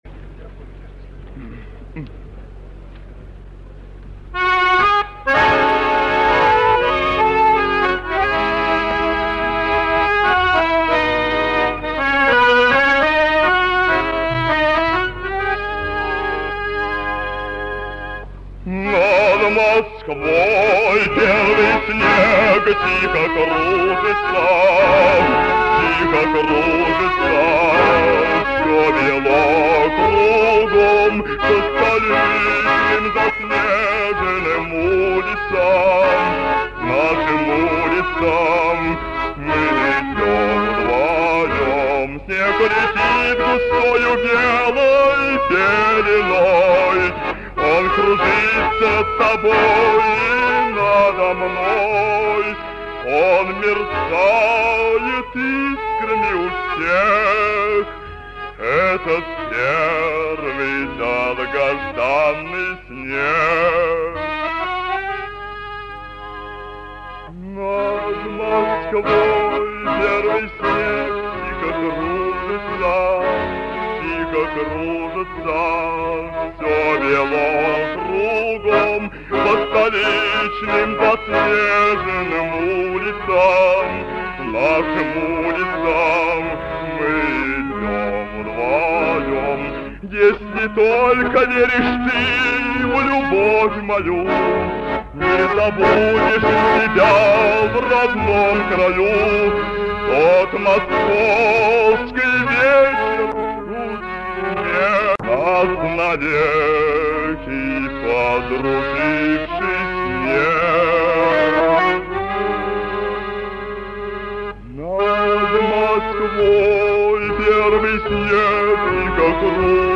ВТОРОЙ МОСКОВСКИЙ ВЕЧЕР-КОНКУРС СТУДЕНЧЕСКОЙ ПЕСНИ